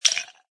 plasticice3.mp3